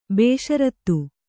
♪ bēṣarattu